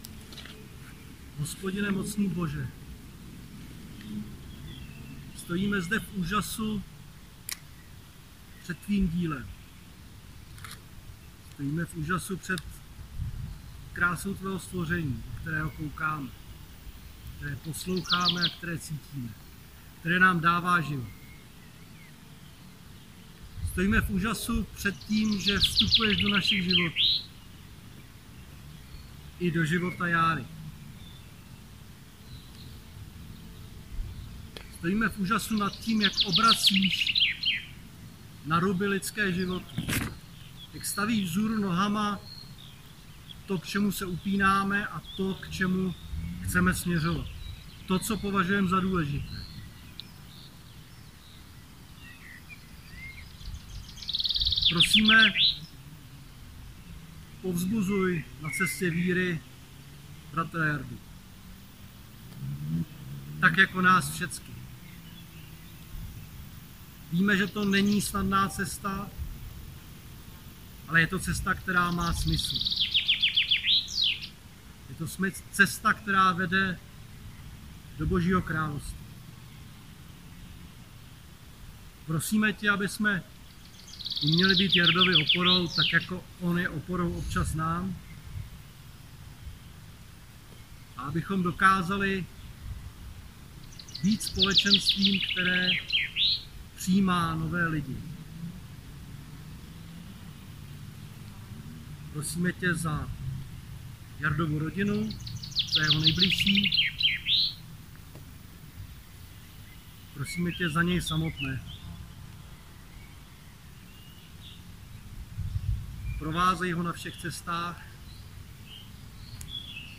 krest-přímluvná-modlitba.mp3